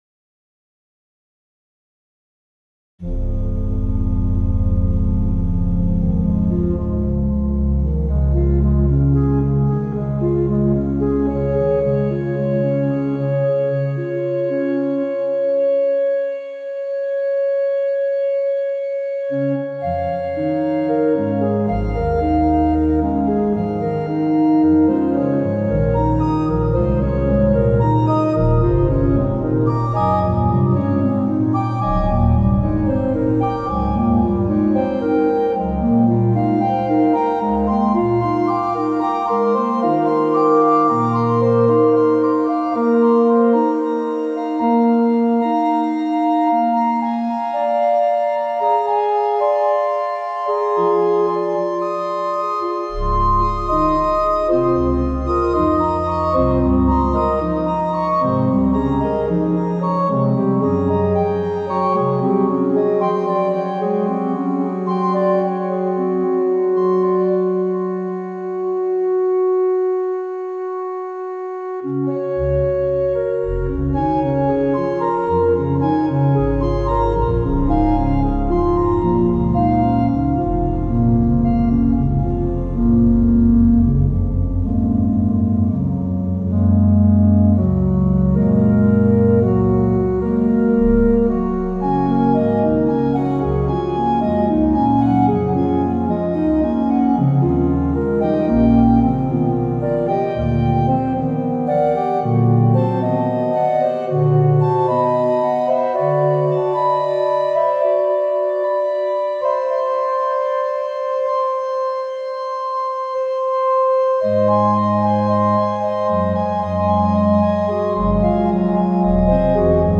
• Sonification by organ (physical modeling)